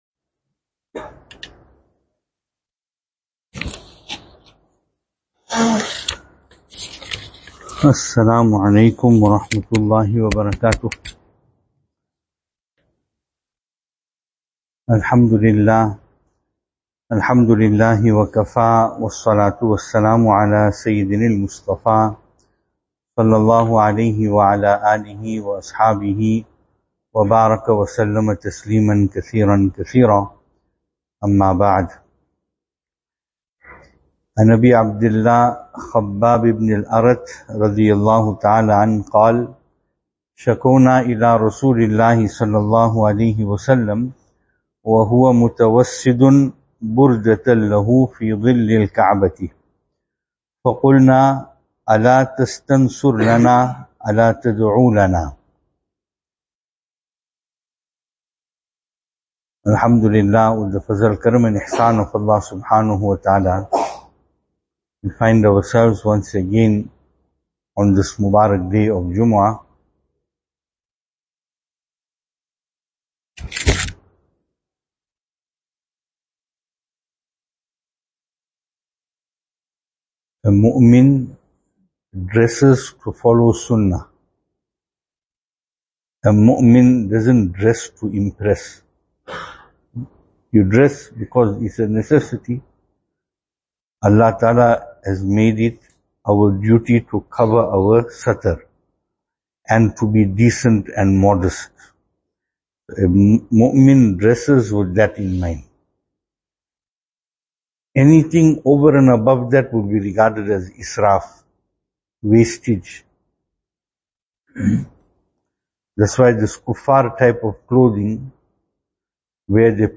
2024-02-02 Narration Of Hadhrat Khabbab Bin Al Arat RadiAllahu anhu Venue: Albert Falls , Madressa Isha'atul Haq Service Type: Jumu'ah